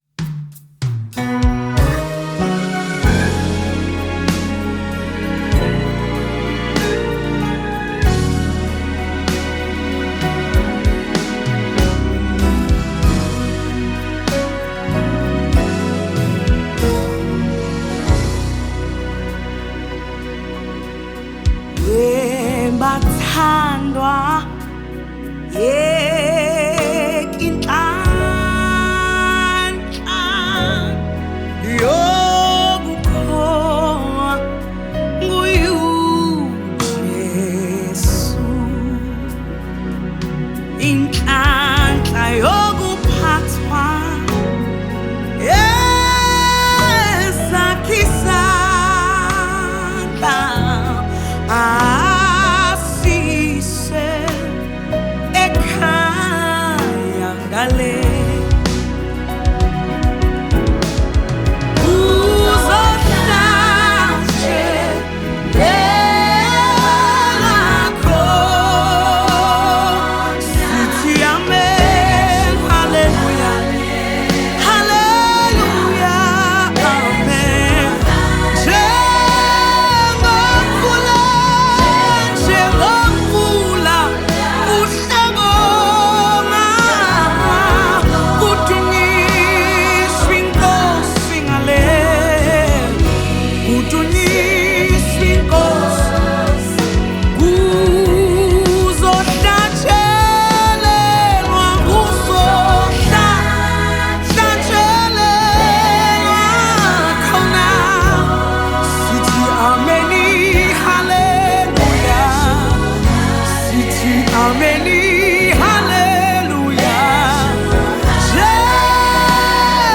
South African Gospel
Genre: Gospel/Christian